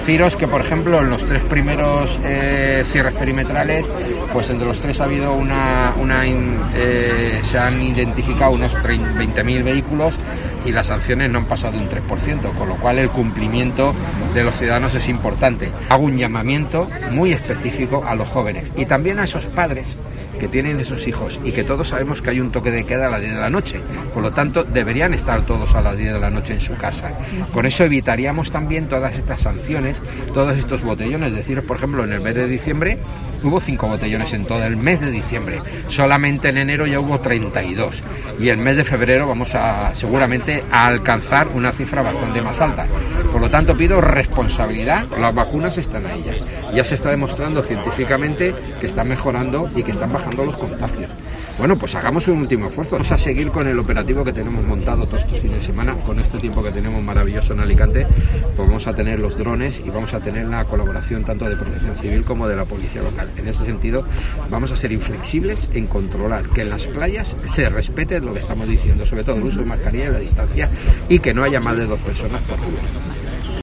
José Ramón González, concejal de Seguridad de Alicante: "Pedimos a los jóvenes que respeten a los demás"